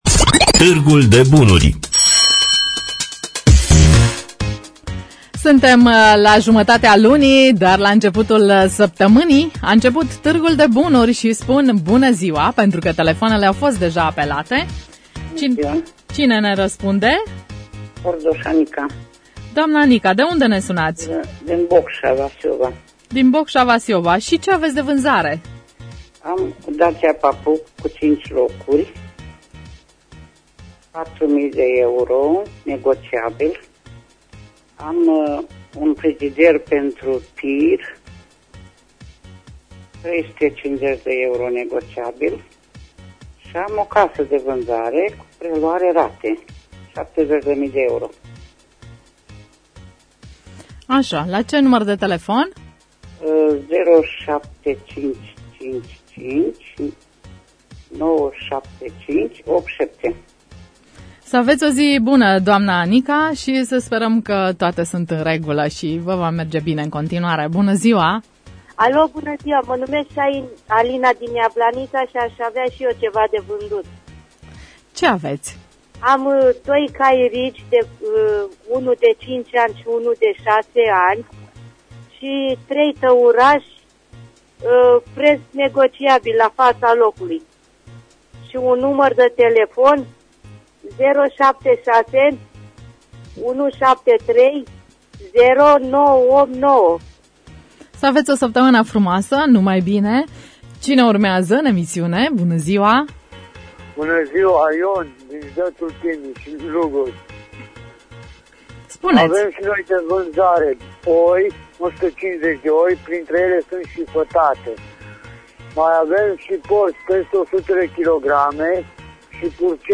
Înregistrarea emisiunii „Târgul de bunuri” de luni, 15.02.2016, difuzată la Radio România Reşiţa.